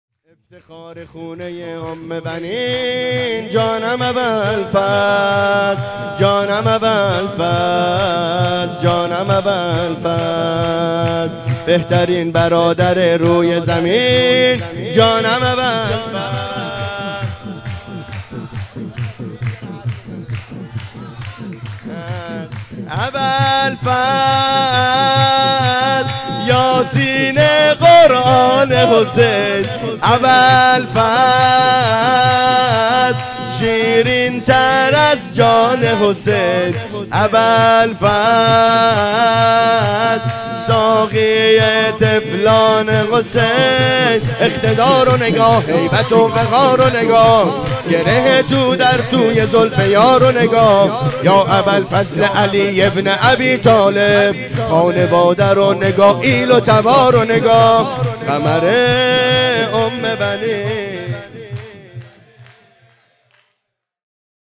جشن اعیاد شعبانیه 1403
جشن ولادت حضرت ابالفضل عباس